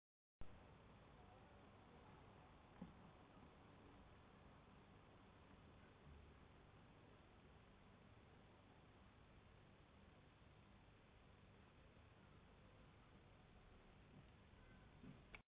华为Supercharge快充头的持续嗡鸣声 - 手机讨论区 - 专门网